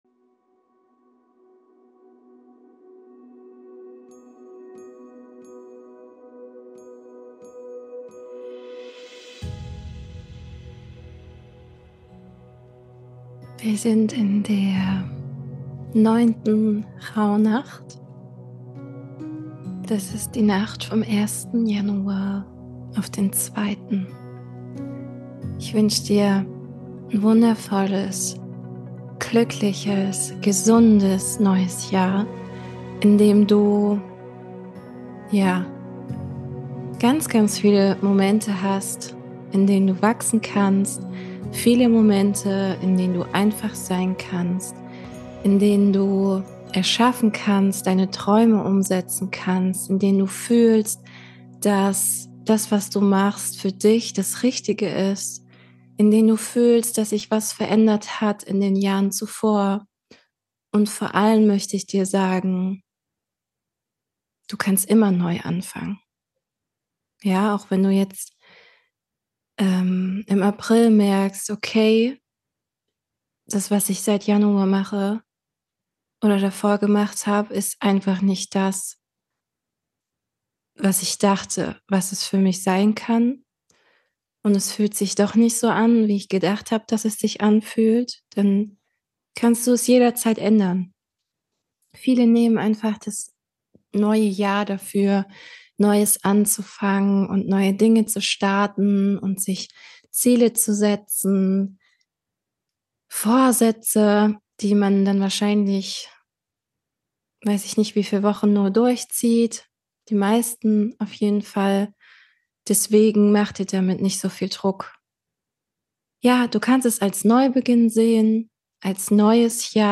Neben den heutigen Journal Fragen gibt’s also eine Meditation [ab ca. Min 08:38] on top.